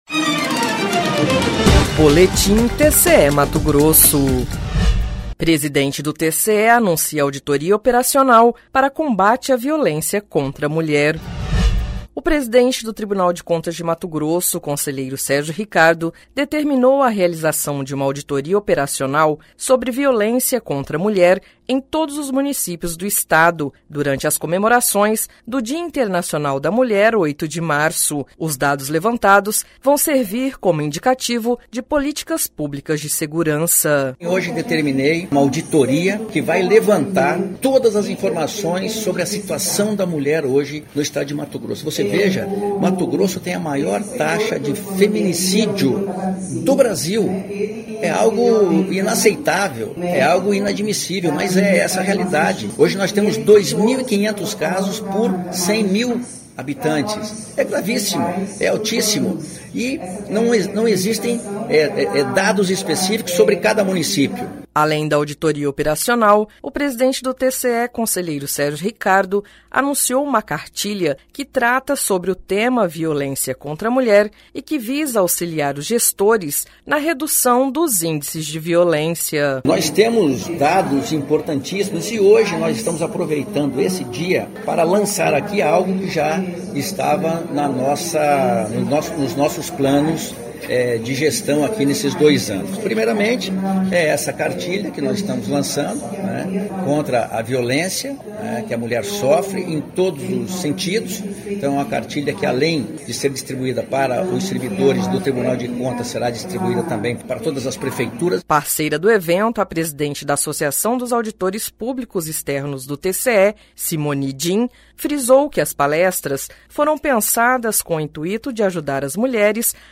Sonora: Sérgio Ricardo – conselheiro presidente do TCE-MT